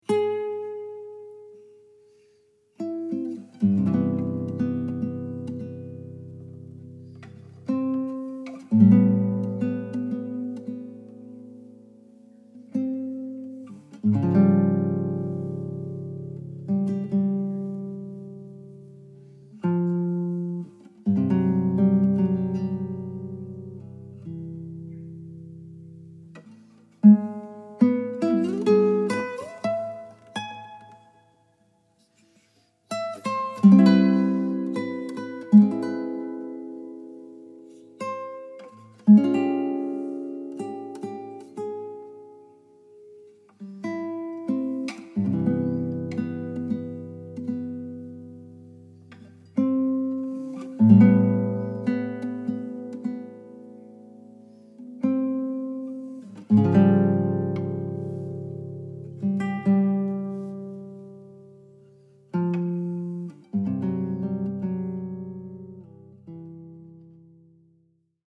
this album of solo guitar pieces is great for all occasions.
A variety of guitars were used during the performances.